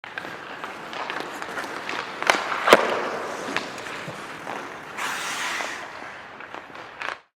Hockey Shot
yt_k4U8ICz7u8M_hockey_shot.mp3